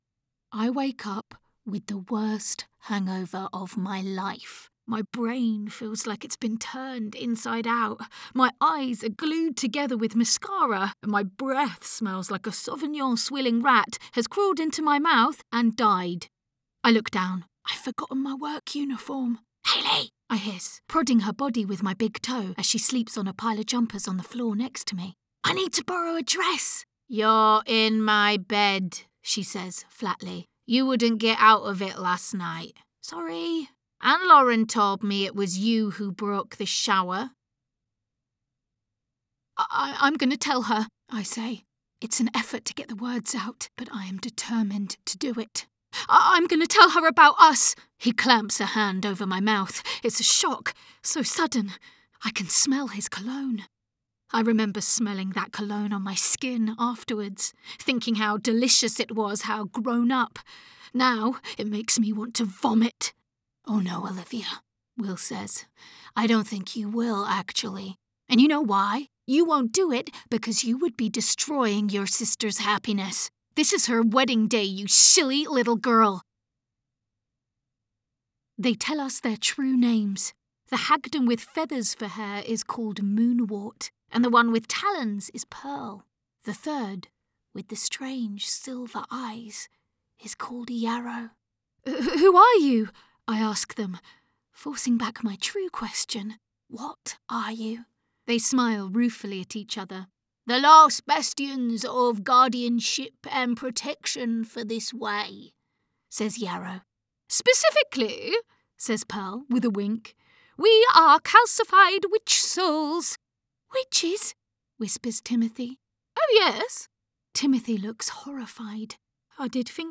• Native Accent: London
• Home Studio